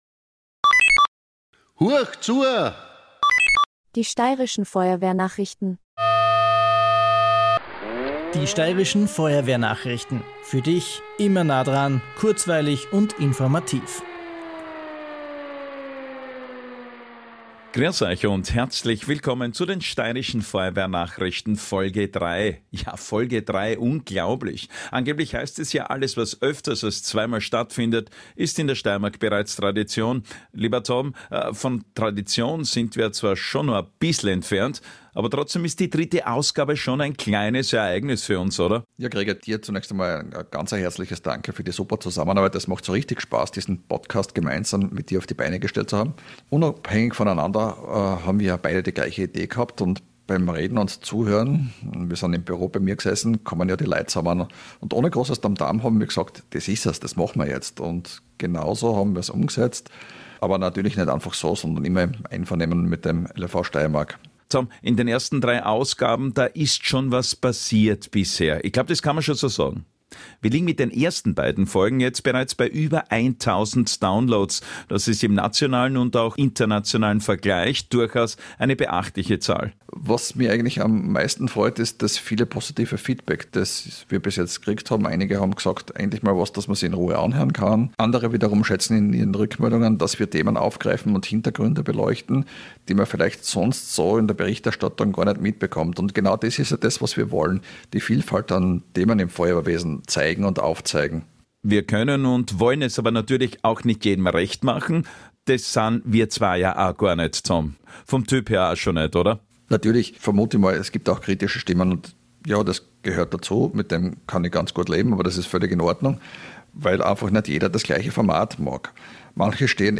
Genau dazu laden wir euch ein: Feuerwehr-Themen aus der Steiermark und für die Steiermark – informativ, kompakt und im Stil einer Nachrichtensendung.